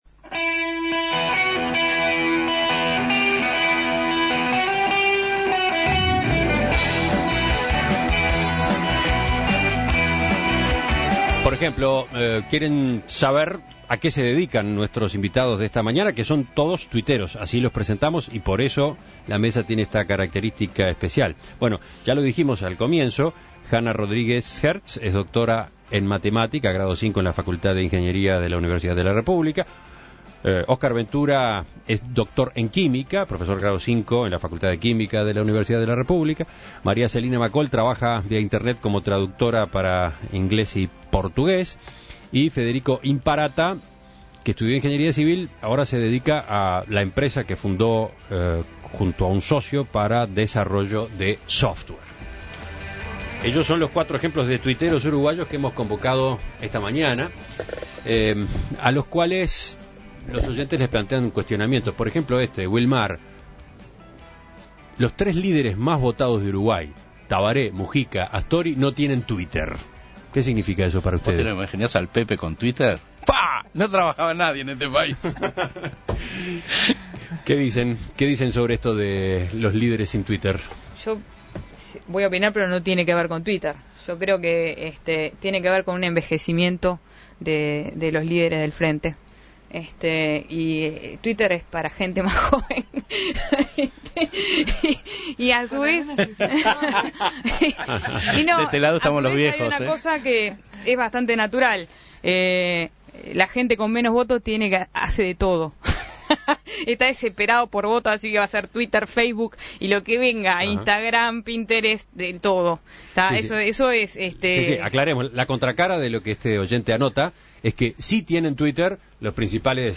Tertulia de tuitstars: los líderes políticos y Twitter